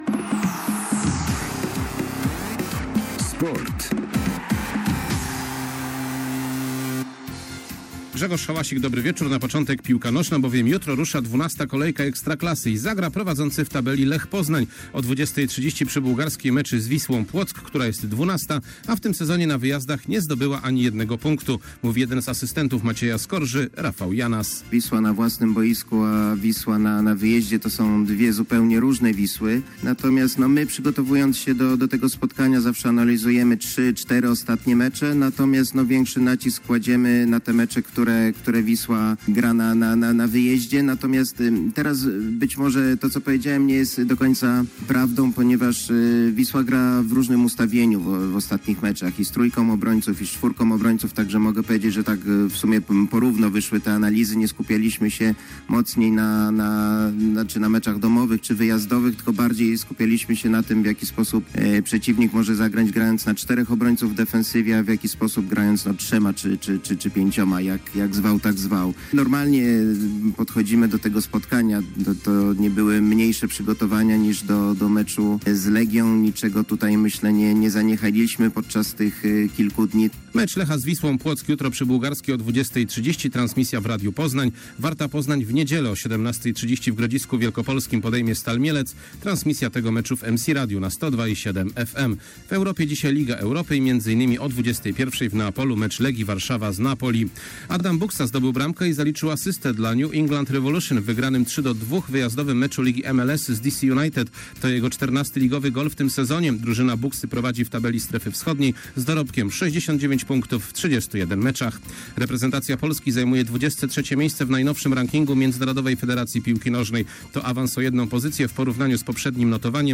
21.10.2021 SERWIS SPORTOWY GODZ. 19:05